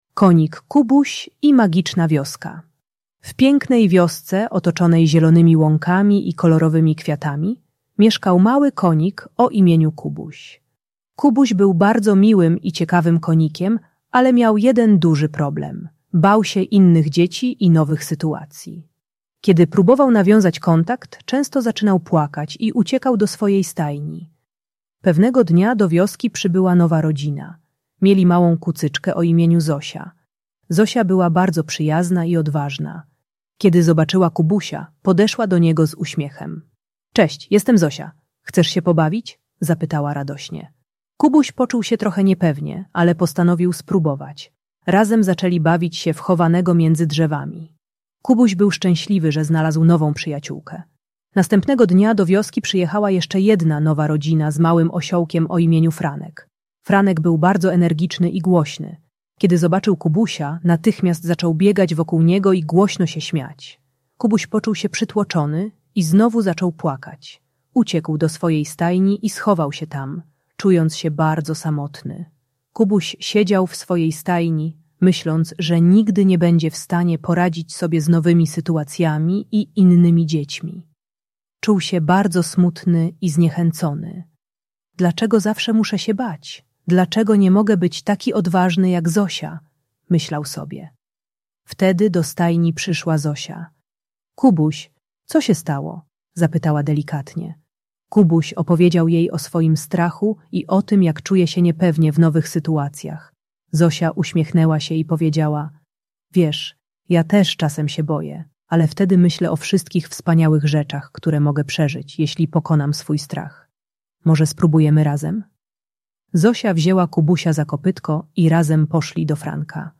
Konik Kubuś i Magiczna Wioska - Lęk wycofanie | Audiobajka